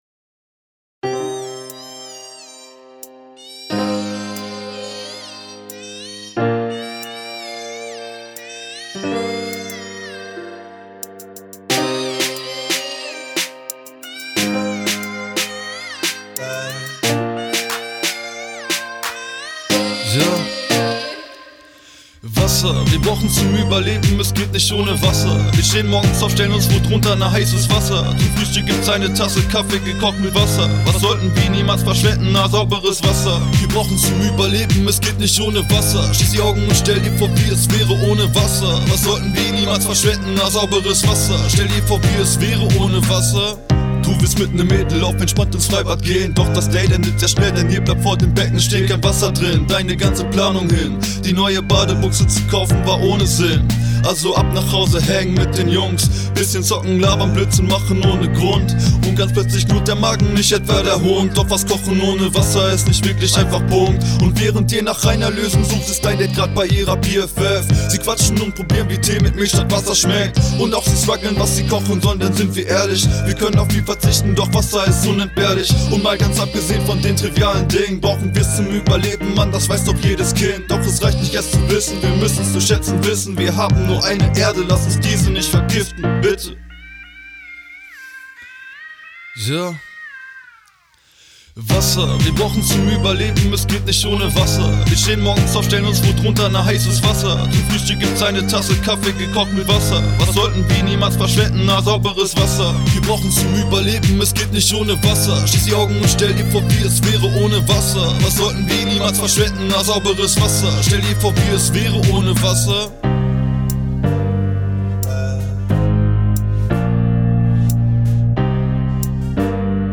Der Wasser-Rap zum Lernerlebnis Trinkwasserschutz
WasserRap.mp3